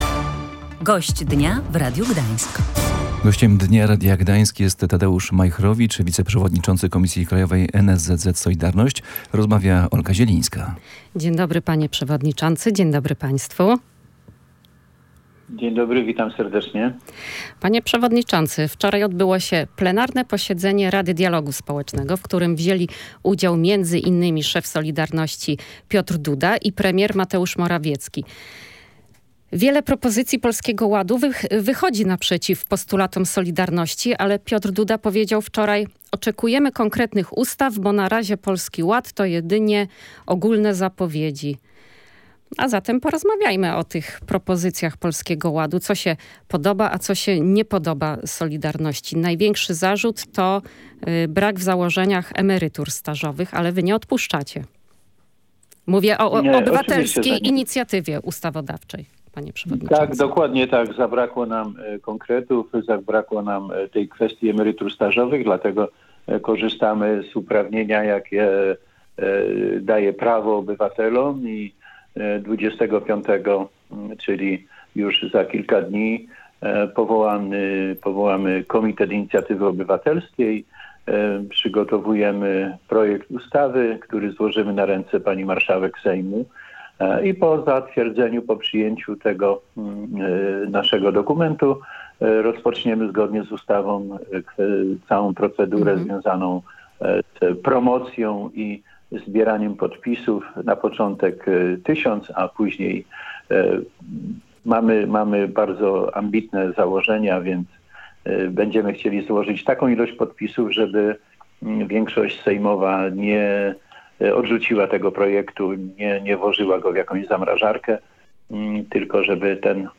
rozmawiała z nim o założeniach Polskiego Ładu w ocenie „Solidarności”. Przyjrzeliśmy się zapowiedzi likwidacji umów śmieciowych, zmianom podatkowym, ułatwieniom dla młodych ludzi na rynku pracy i propozycjom dotyczącym emerytur.